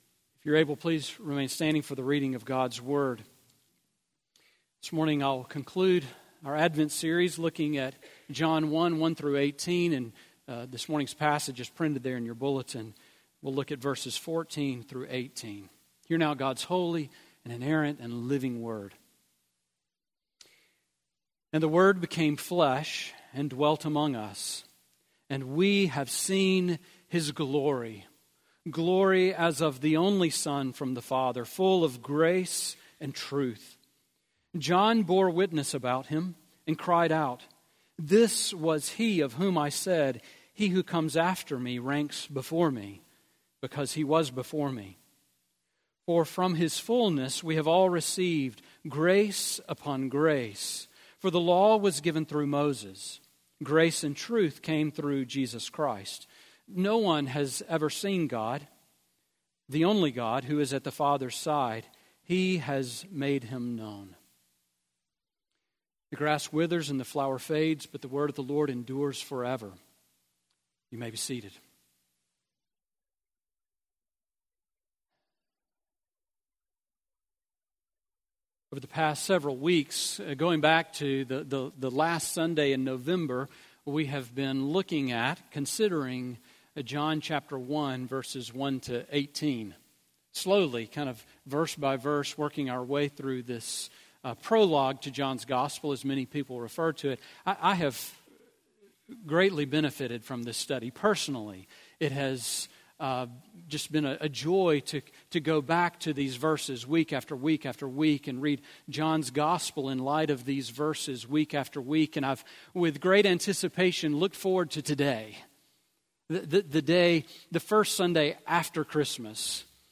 Sermon on John 1:14-18 from December 30